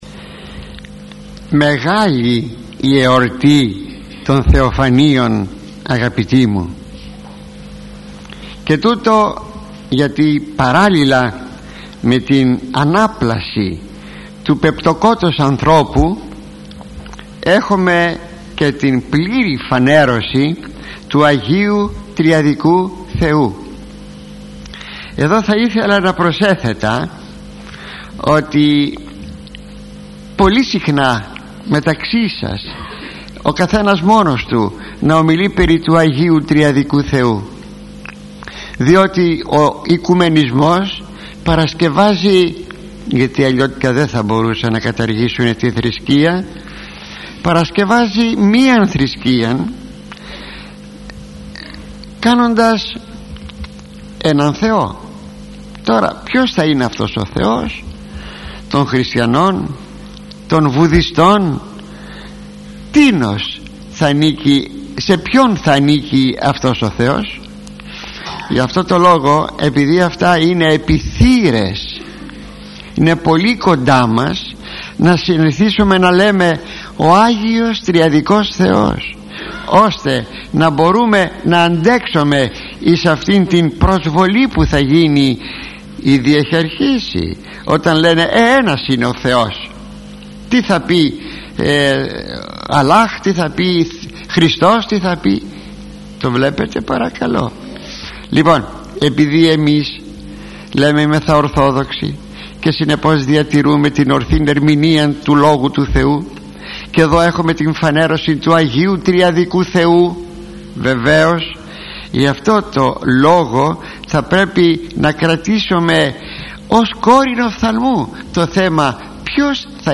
Οι αγαθές συνέπιες του Βαπτίσματος του Χριστού – ηχογραφημένη ομιλία του Μακαριστού Αρχιμ.